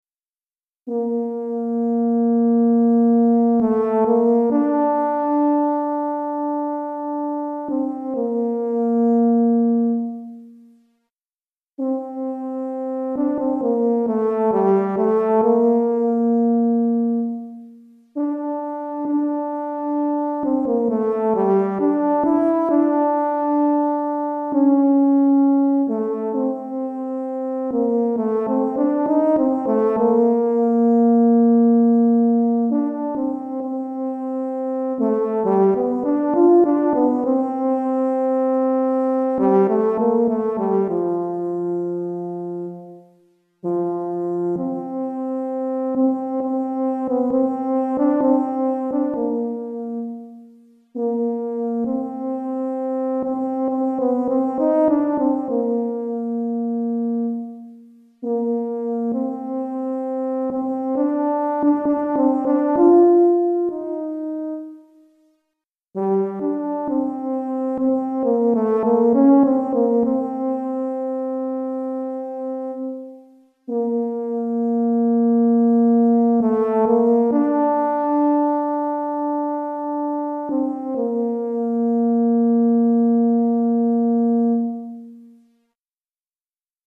Tuba Solo